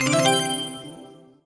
collect_item_jingle_06.wav